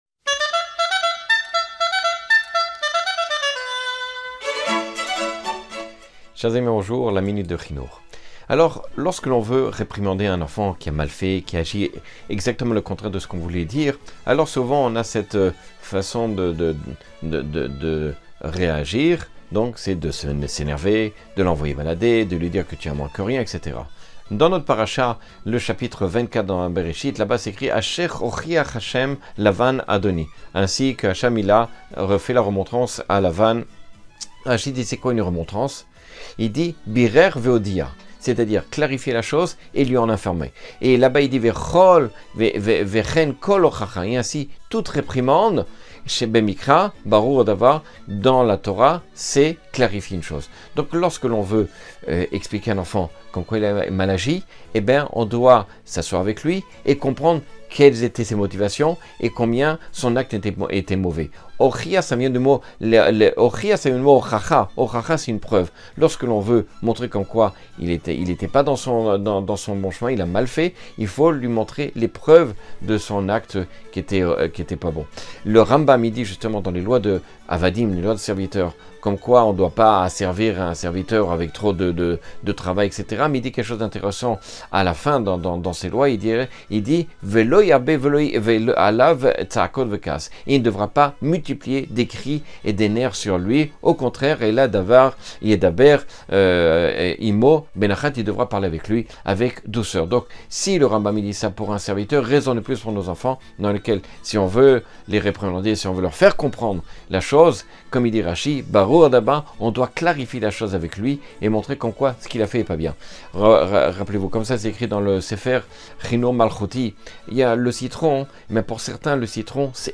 Cours de 1 minute et des poussières sur l’éducation (le ‘hinoukh).